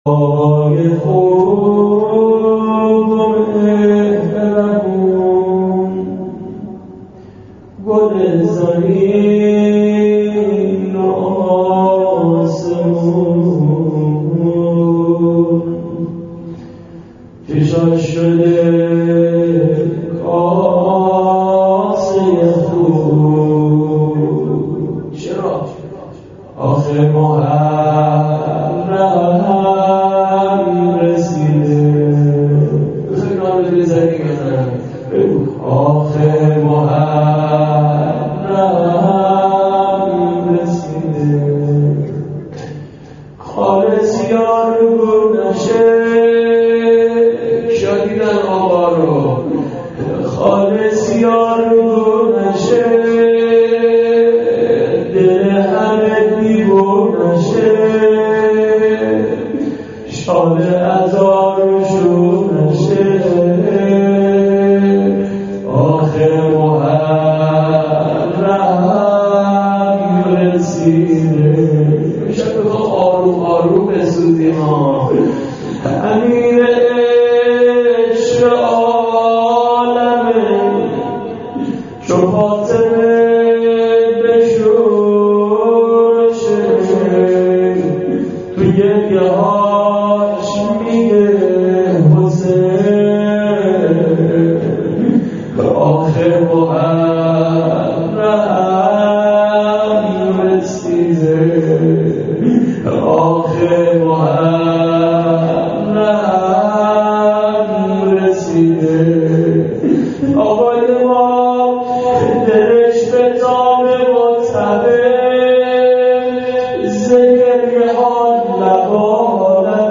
مناجات با امام زمان شروع محرم.MP3